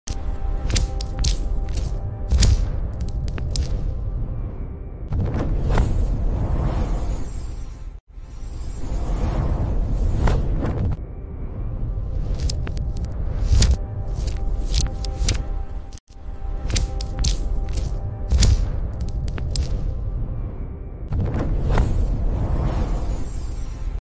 An AI hand forges a sound effects free download
An AI hand forges a star from pure plasma! 🤯 The sound of controlled energy.